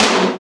TOM TM034.wav